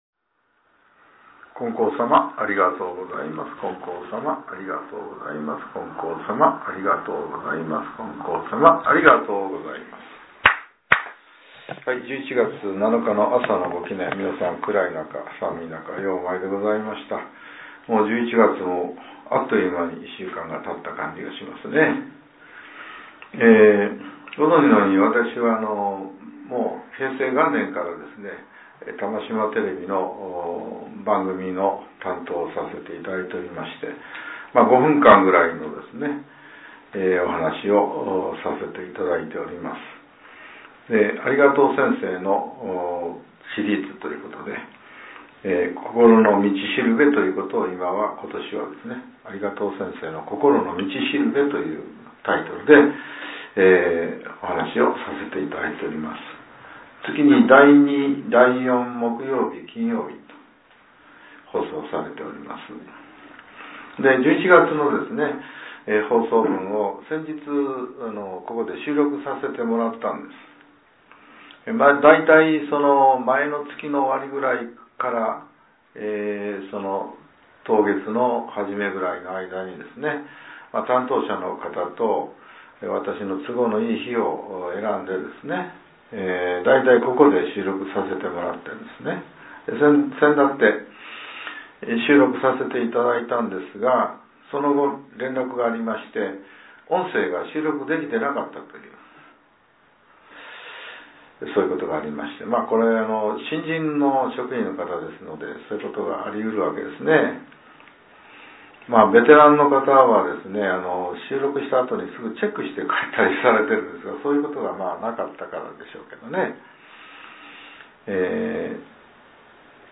令和７年１１月７日（朝）のお話が、音声ブログとして更新させれています。